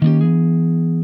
SLIDECHRD3.wav